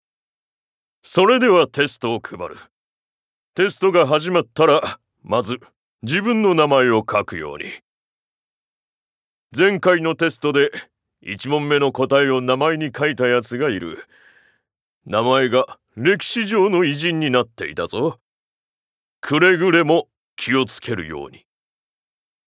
Voice Sample
ボイスサンプル
セリフ４